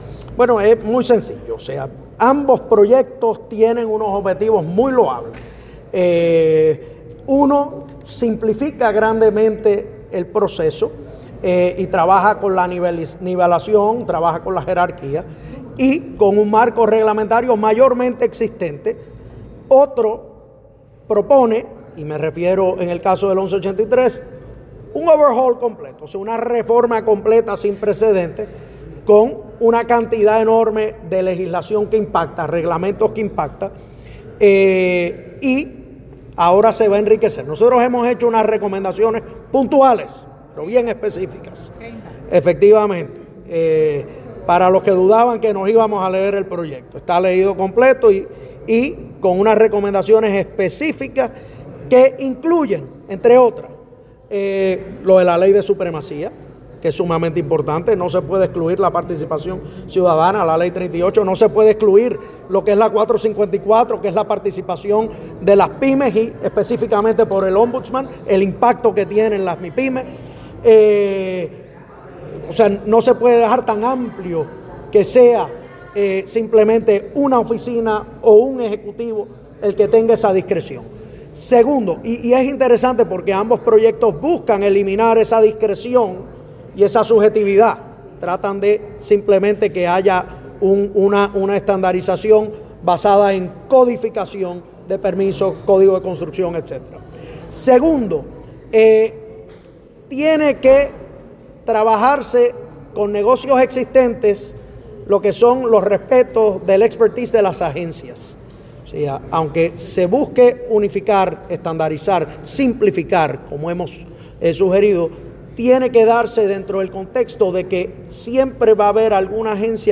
(13 de abril de 2026)- Hoy se llevó a cabo la Vista pública conjunta de la Comisión de Innovación, Reforma y Nombramientos que dirige el presidente del Senado, Thomas Rivera Schatz, y la Comisión de Planificación, Permisos, Infraestructura y Urbanismo que preside el senador Héctor “Gaby” González López.